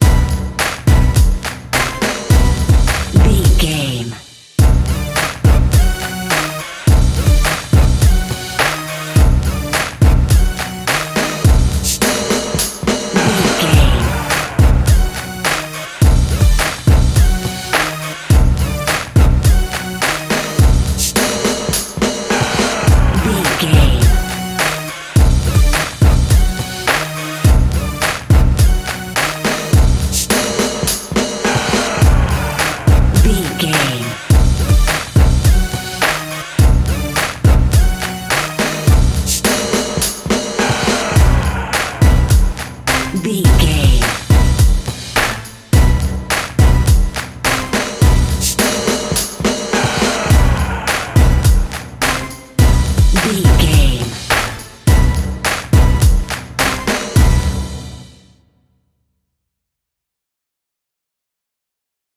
Aeolian/Minor
drum machine
synthesiser
funky